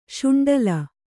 ♪ śuṇḍala